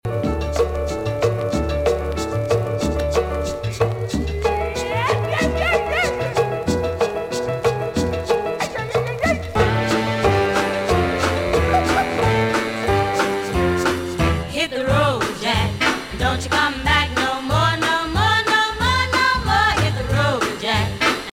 1960s Latin